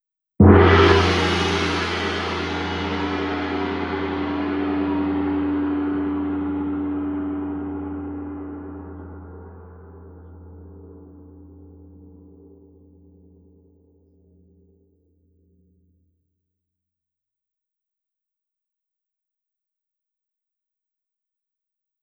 Chamei meu gongo feng de Surtur, pela qualidade ardente de seu som, e o estrondoso gongo da Dark Star é chamado de Behemoth.
Convidamos você a desfrutar e usar em seus próprios rituais, os seguintes arquivos de som de gongo das Casas Negras passadas e presentes.
Gongo de bronze “Surtur”, Casa Negra, Distrito de Bruxaria (derrame)